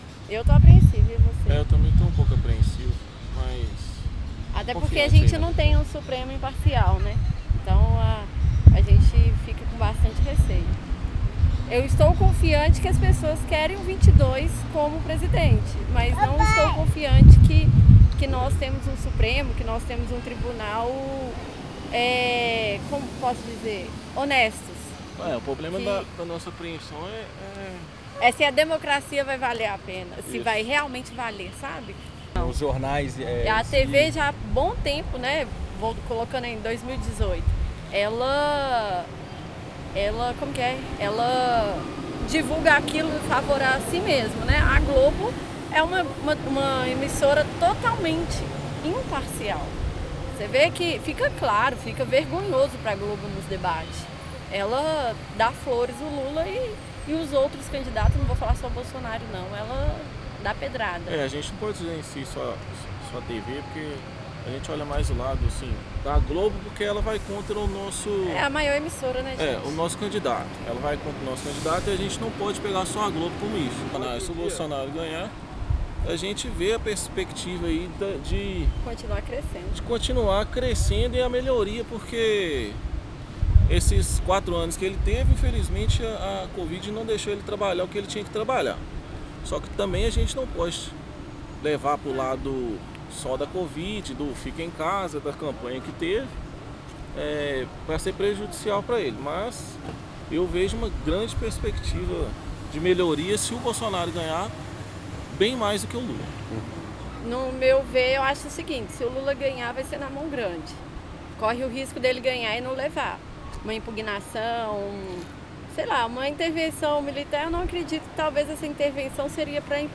Confira o depoimento do casal durante a conversa: